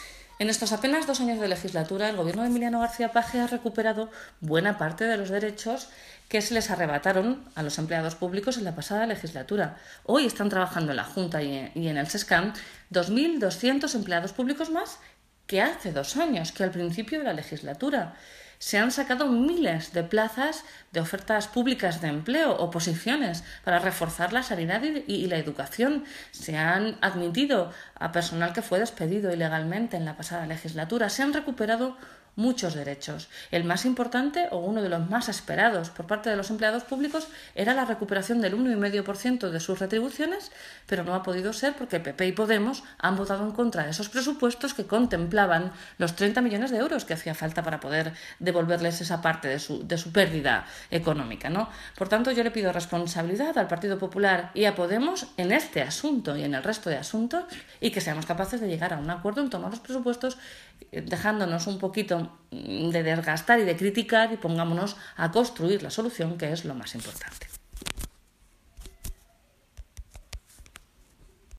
La portavoz  del Grupo Parlamentario Socialista en las Cortes de Castilla-La Mancha, Blanca Fernández, ha querido reconocer los avances que en sólo 23 meses de gobierno del presidente García-Page han tenido los empleados públicos de la región.
Cortes de audio de la rueda de prensa